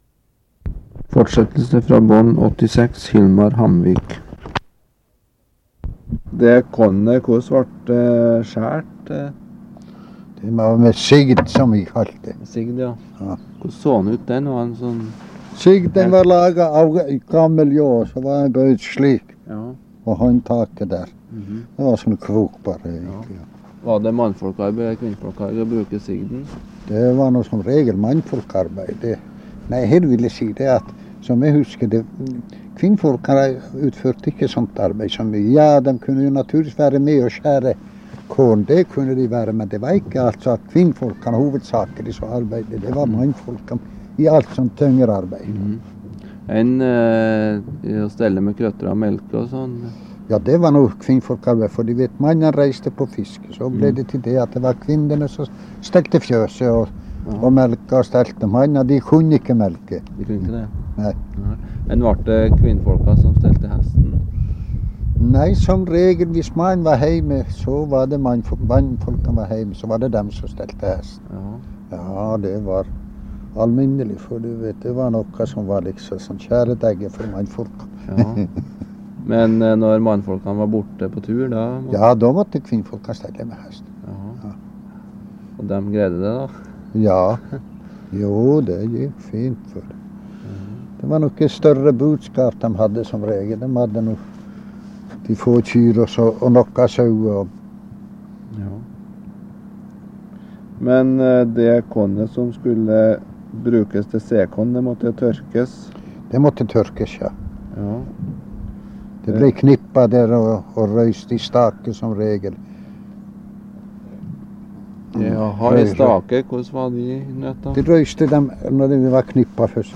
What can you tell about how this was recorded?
Sted: Lyngen, Furuflaten